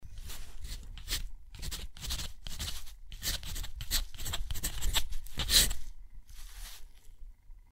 Звуки пера по бумаге
Написал пером и подчеркнул